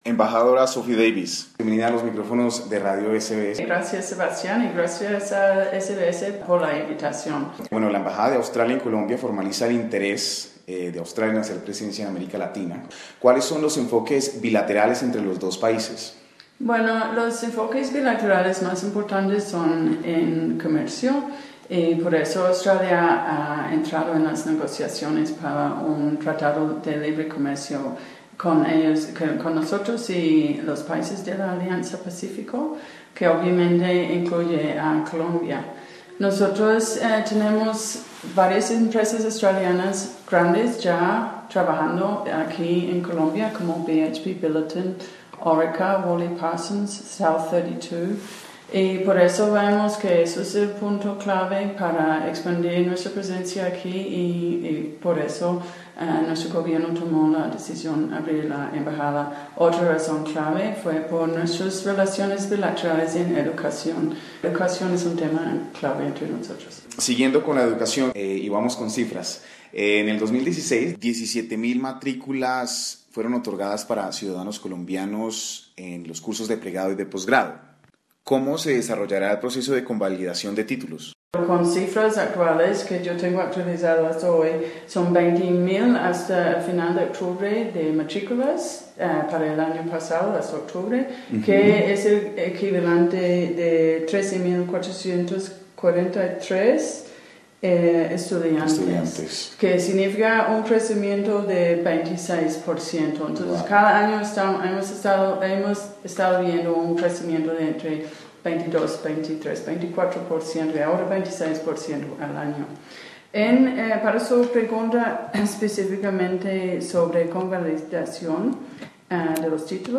conversó con la embajadora de Australia en Bogotá, Sophie Davies